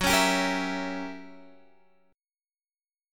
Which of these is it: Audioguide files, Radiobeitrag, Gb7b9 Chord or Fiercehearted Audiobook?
Gb7b9 Chord